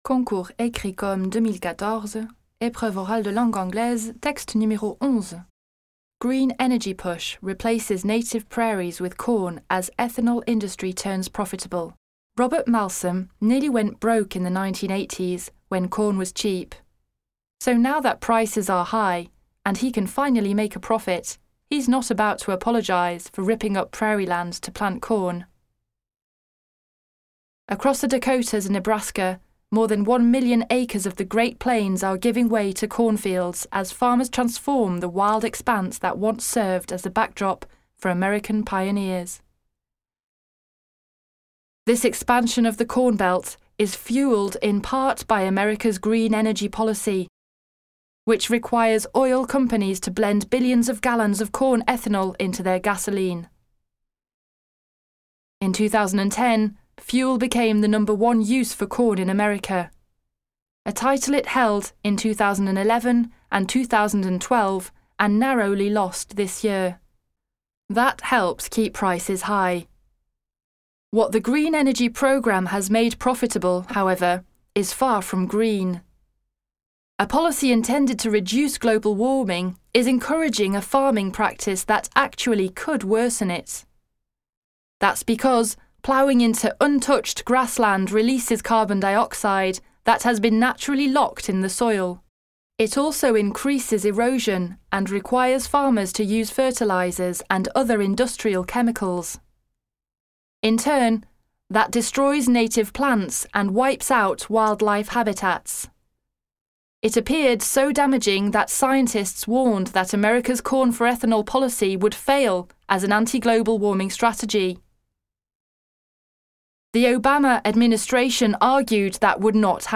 Ce sont des enregistrements sonores, au format mp3, d'extraits d'articles de presse.